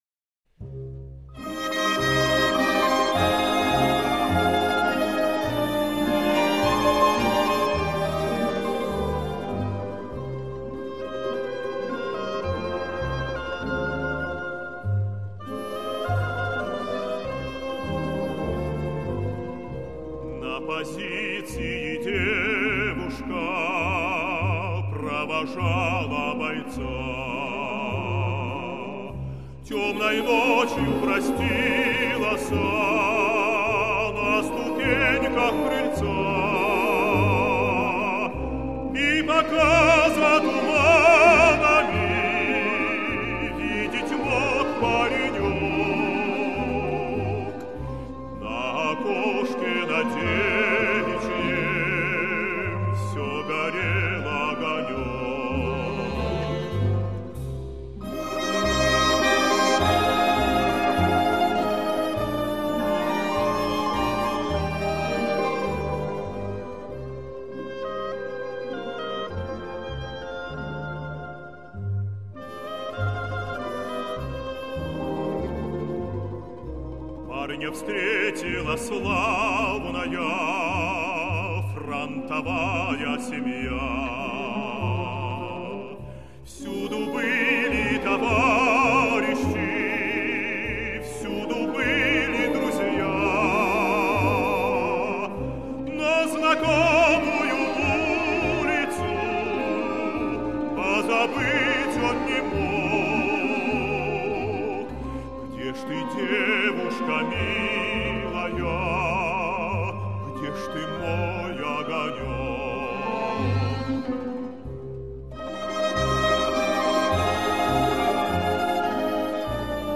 Певцы
Режим: Stereo